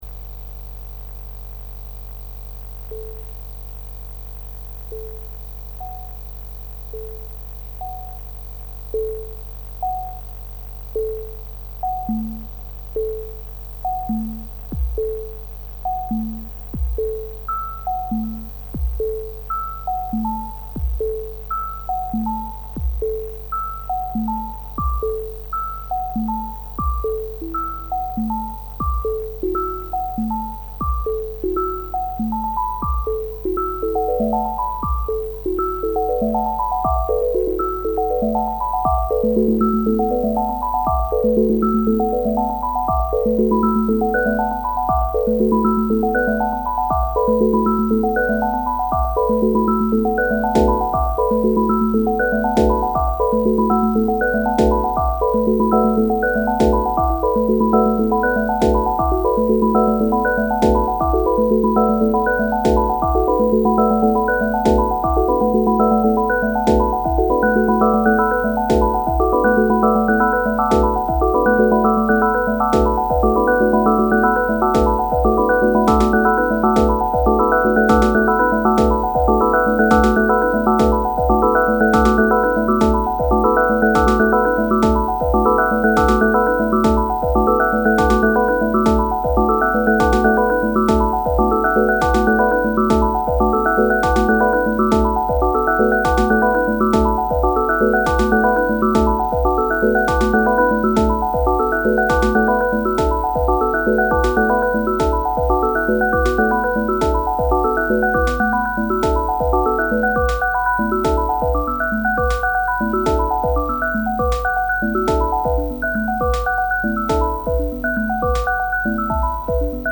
催眠曲
今天录了一小首，放上来，不知道能不能坚持下去，要是有朝一日汇总起来一堆催眠曲，失眠的时侯也好随机找来听听，不求疗效，但求舒心。